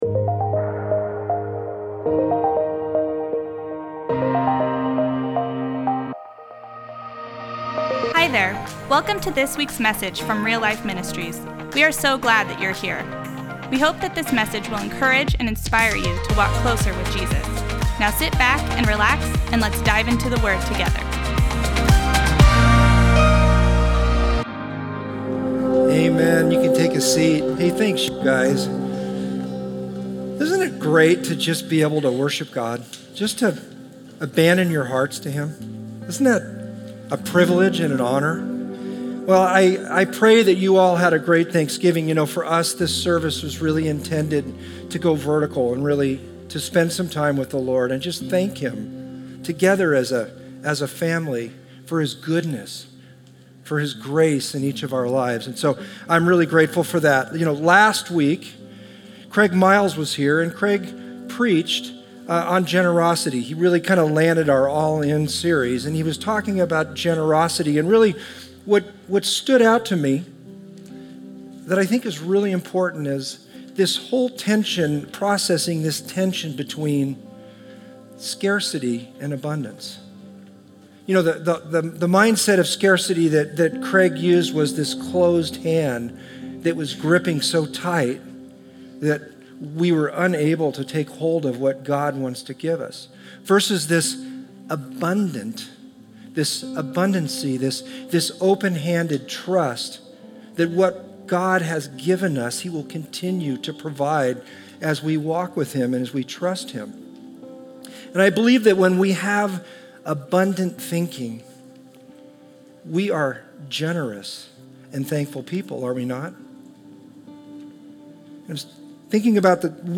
1. What about the sermon resonated with you?